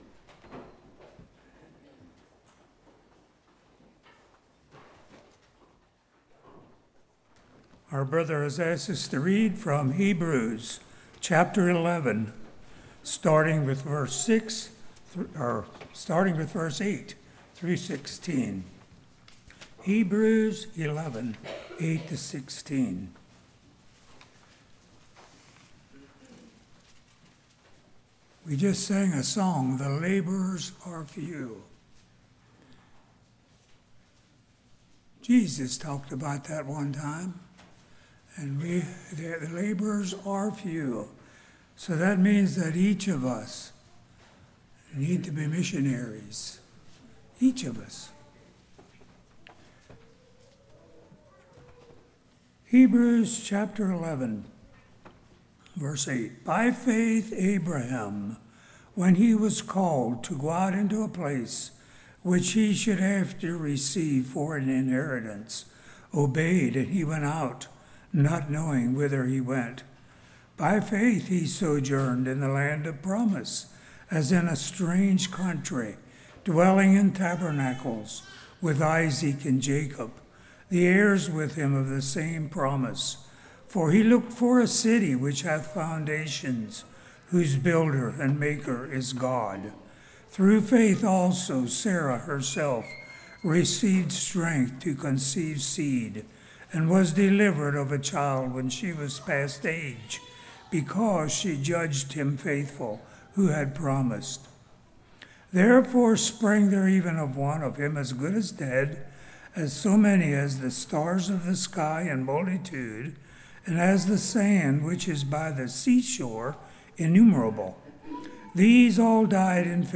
Hebrews 11:8-16 Service Type: Morning Why Church?